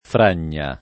fragna [ fr # n’n’a ]